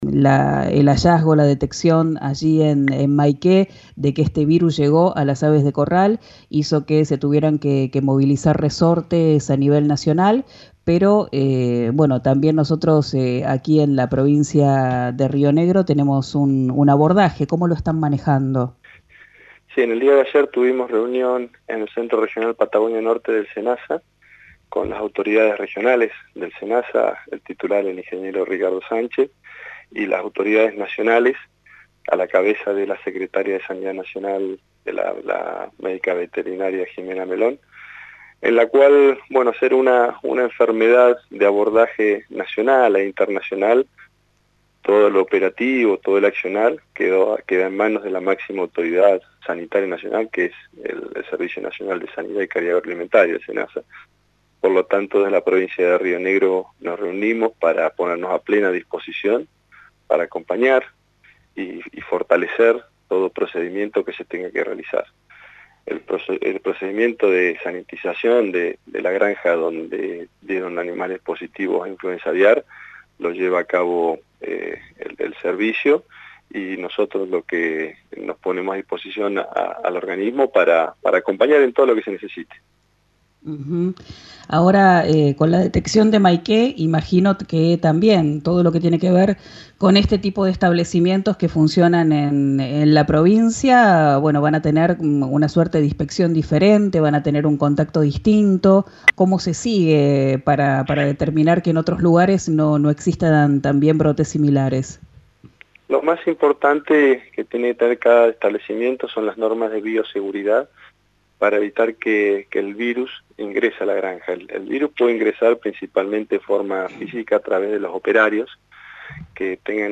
Así lo confirmó a «Quien dijo verano», por RÍO NEGRO RADIO, el secretario de Ganadería de Río Negro, Norberto Tabaré Bassi.